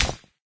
plasticstone.ogg